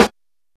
Hat (63).wav